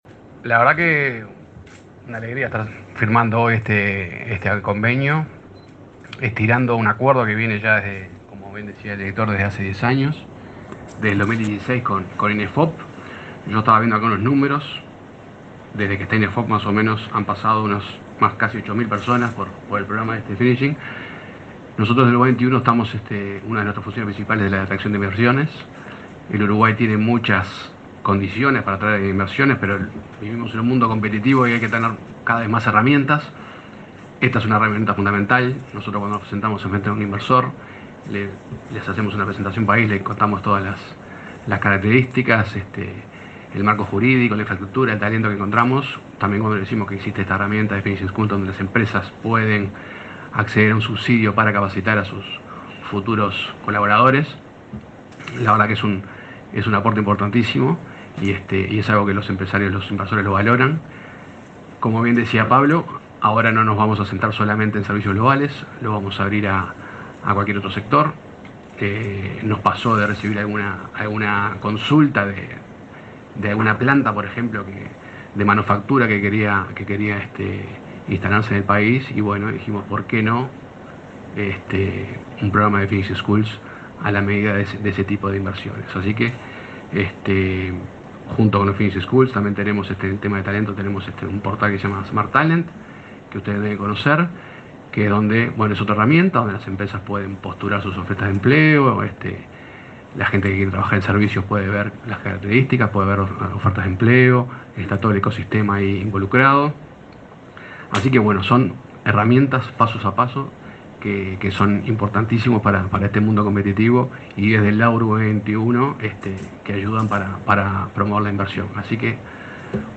Palabras de autoridades en convenio entre Inefop y Uruguay XXI